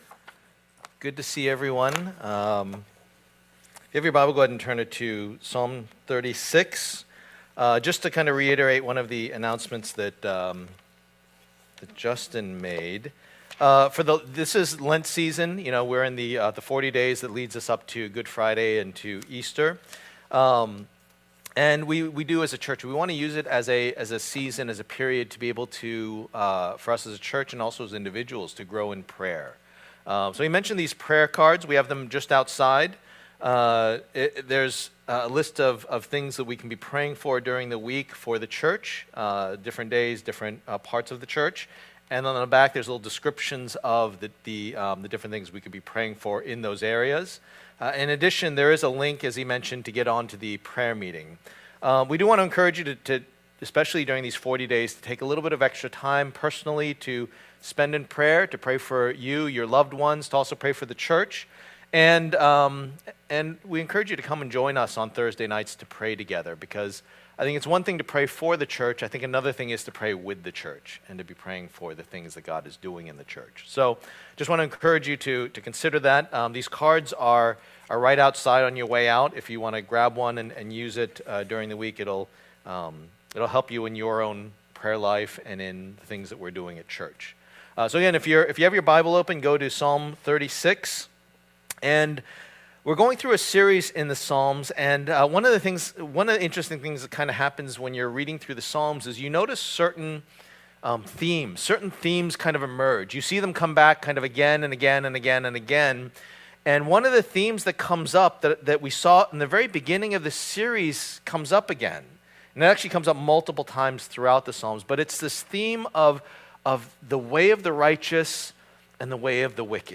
Passage: Psalm 36:1-12 Service Type: Lord's Day